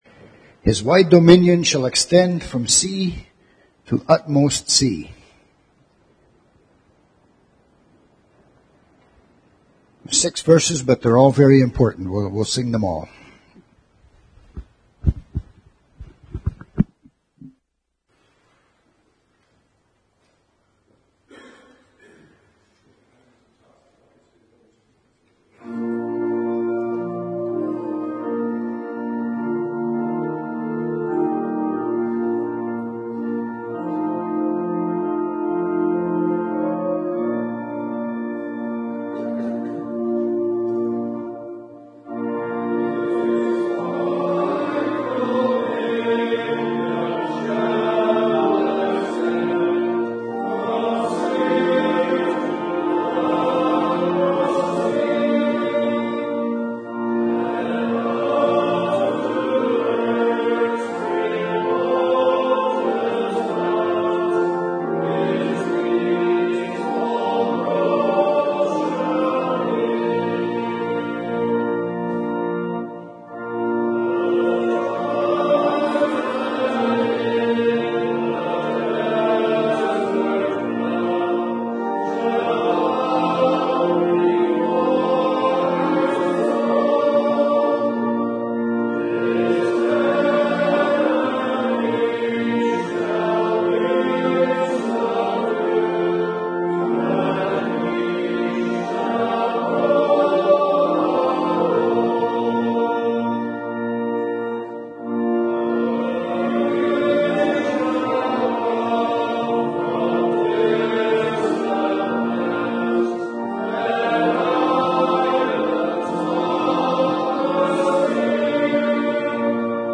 2016 Q&A Session - Heritage Reformed Churches